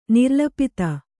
♪ nirlapita